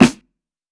TC SNARE 07.wav